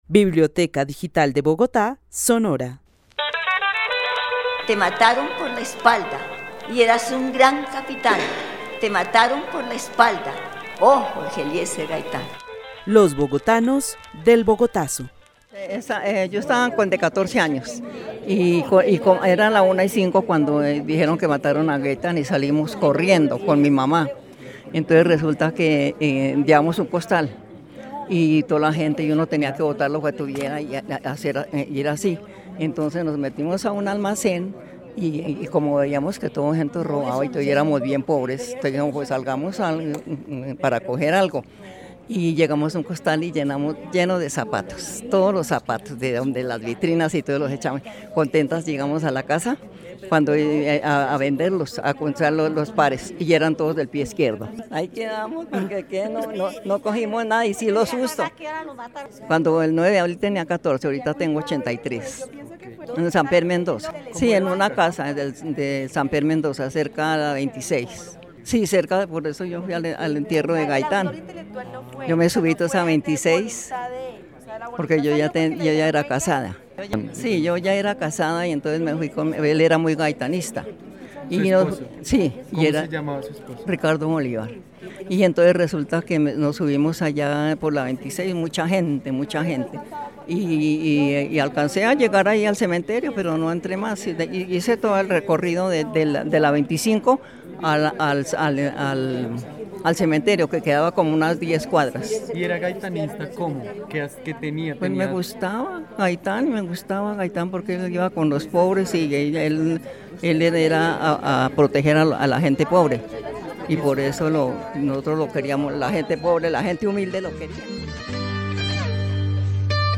Narración oral de los hechos sucedidos en Bogotá el 9 de abril de 1948. Cuenta que vivía en el Barrio Samper Mendoza y pudo asistir al entierro de Jorge Eliecer Gaitán ya que era su seguidora. El testimonio fue grabado en el marco de la actividad "Los bogotanos del Bogotazo" con el club de adultos mayores de la Biblioteca El Tunal.